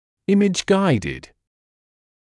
[‘ɪmɪʤ ‘gaɪdɪd][‘имидж ‘гайдид]выполняемый с помощью изображения на мониторе в режиме реального времени